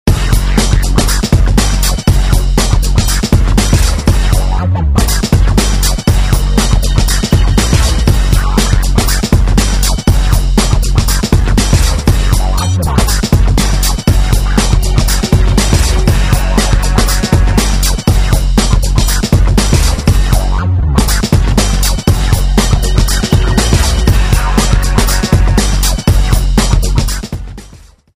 дабстеп и хип-хоп это совершенно разные вещи , прошу не путать ок?